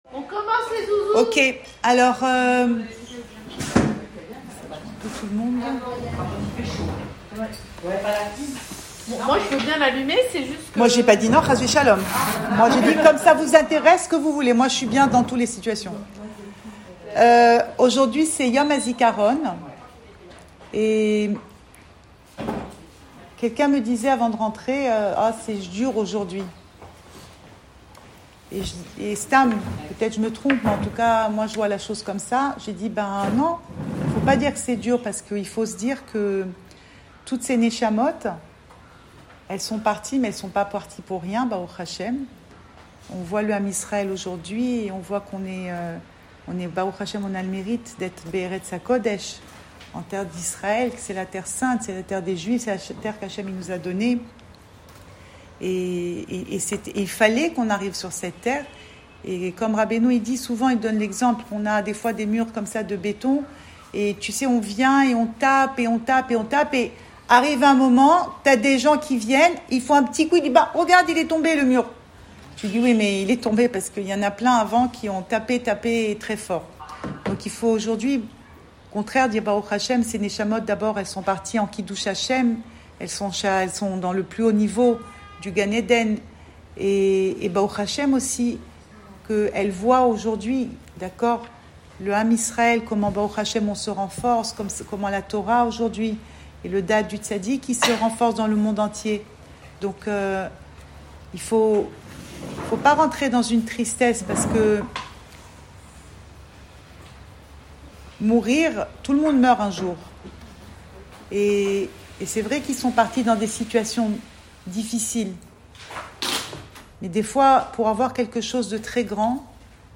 Cours audio Le coin des femmes Pensée Breslev - 4 mai 2022 5 mai 2022 Pas d’inquiétude. Enregistré à Tel Aviv